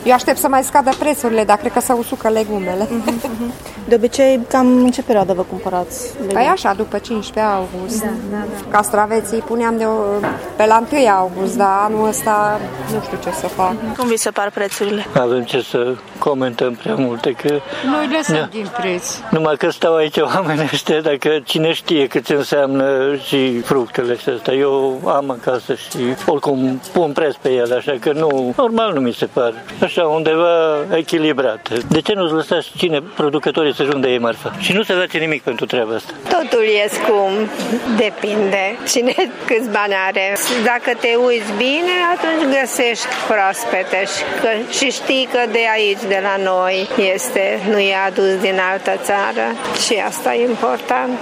Pe de altă parte, cei mai mulți cumpărători se plâng de prețuri mari și așteaptă scăderea lor pentru proviziile de iarnă: